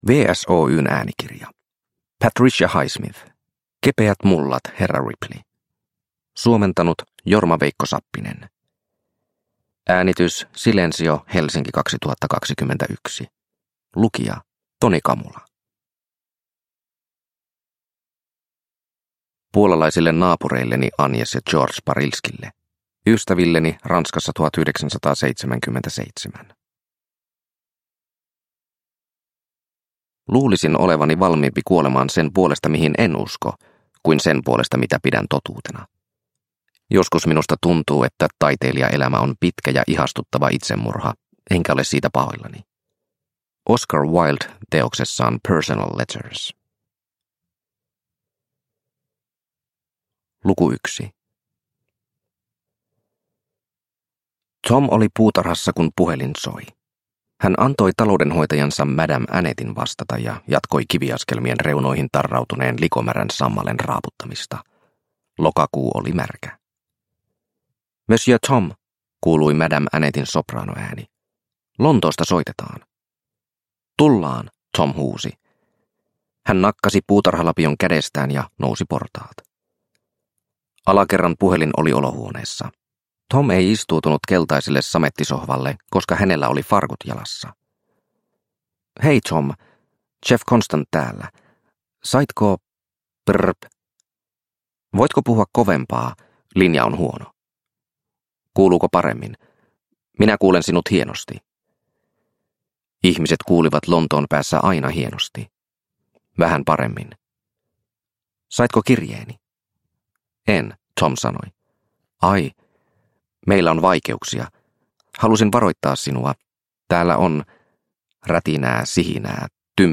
Kepeät mullat, herra Ripley – Ljudbok – Laddas ner